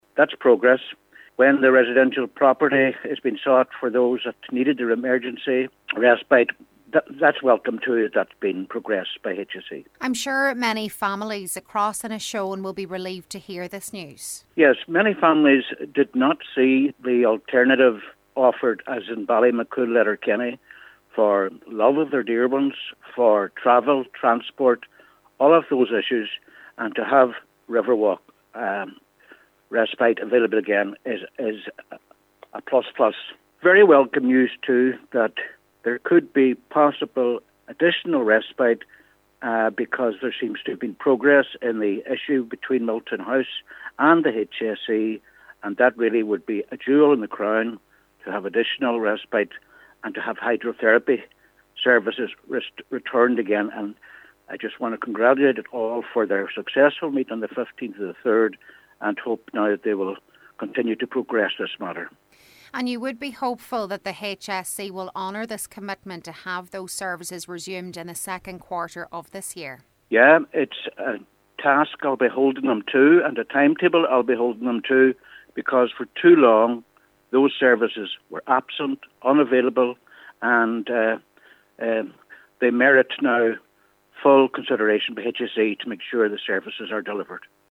Councillor Albert Doherty says he will be keeping the pressure on to ensure the HSE delivers on its commitment: